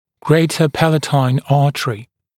[‘greɪtə ‘pælətaɪn ‘ɑːtərɪ][‘грэйтэ ‘пэлэтайн ‘а:тэри]большая небная артерия